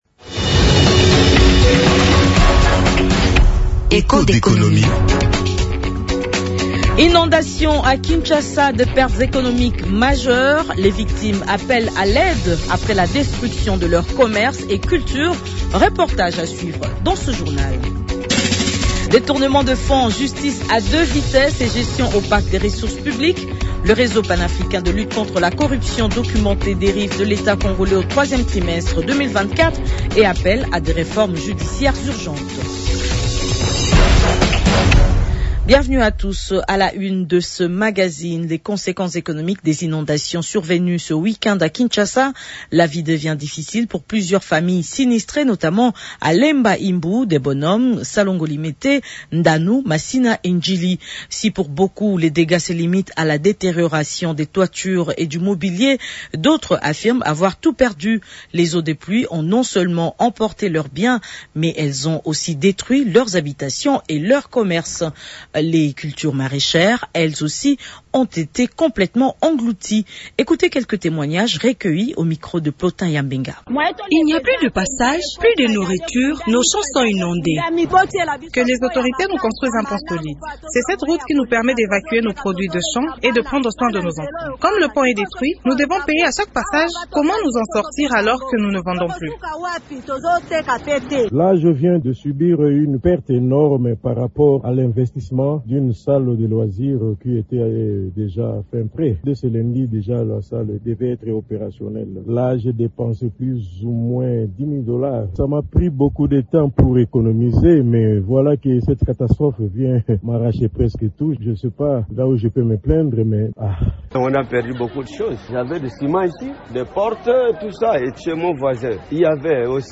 Kinshasa : lourd bilan économique après les inondations, les sinistrés témoignent